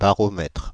ÄäntäminenFrance (Île-de-France):
• IPA: /ba.ʁɔ.mɛtʁ/